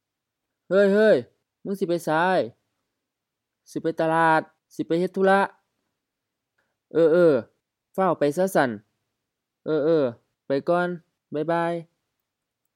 BCF01 Greetings and leave takings (informal) — Dialogue B
Notes: tones unclear